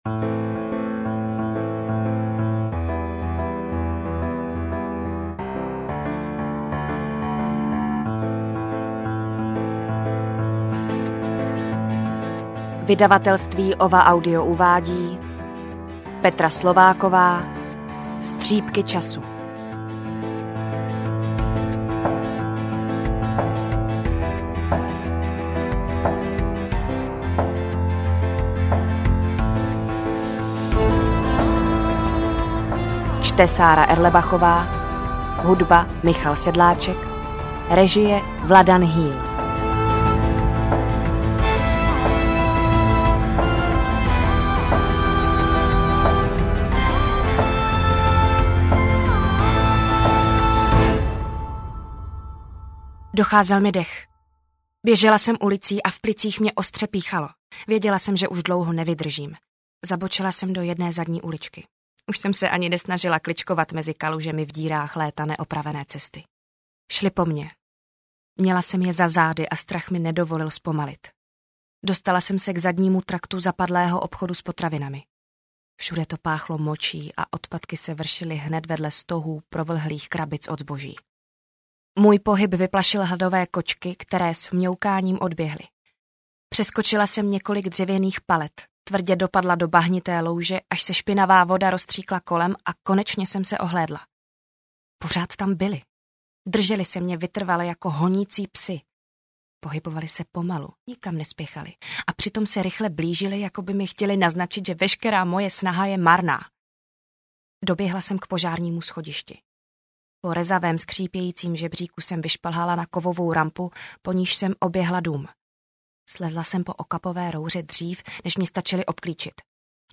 Interpret/Interpretka
AudioKniha ke stažení, 6 x mp3, délka 3 hod. 50 min., velikost 210,0 MB, česky